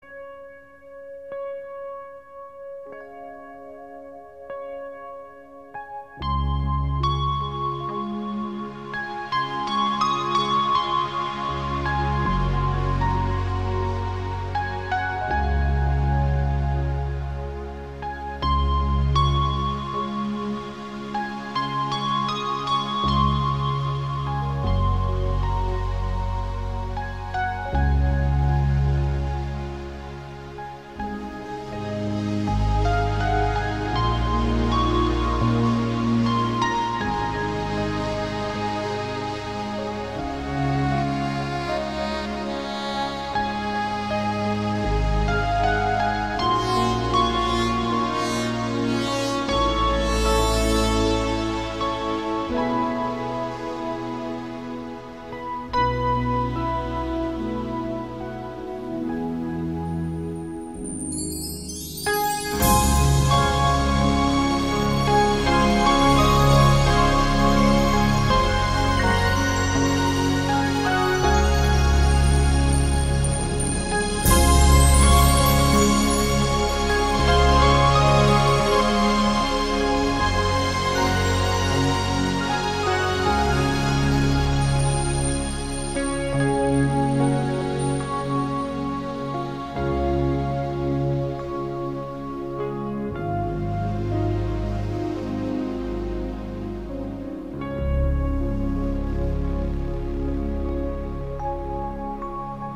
etérea e inmaculada composición musical